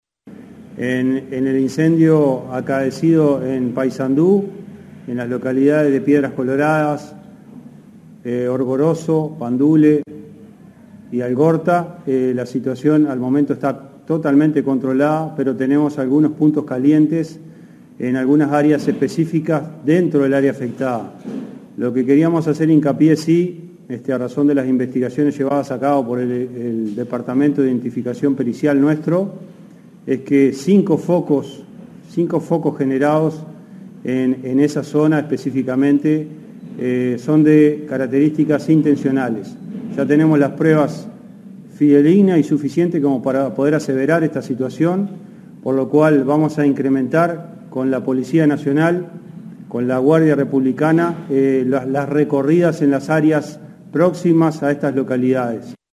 El director Nacional de Bomberos, Ricardo Riaño, declaró que cinco focos de los incendios forestales que afectaron al departamento de Paysandú fueron intencionales.